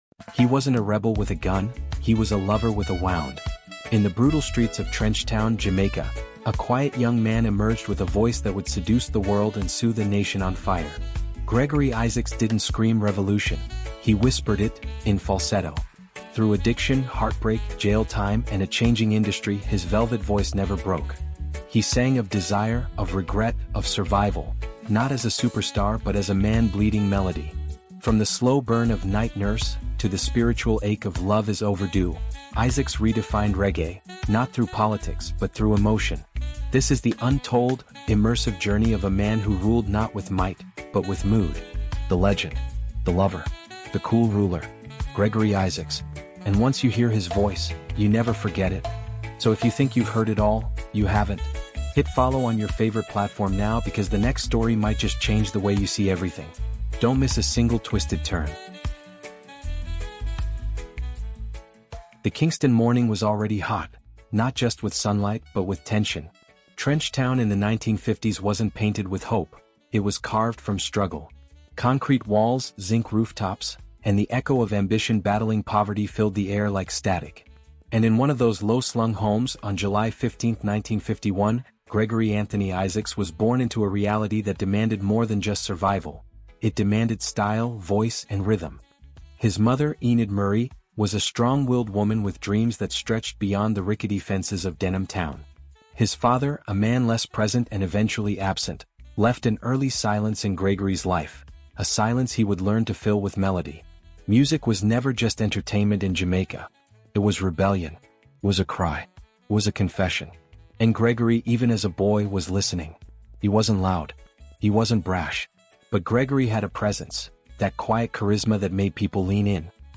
CARIBBEAN HISTORY: Gregory Isaacs — The Cool Ruler of Lovers Rock is a deeply immersive, fact-based documentary audiobook exploring the powerful legacy of one of the most iconic voices in Caribbean music.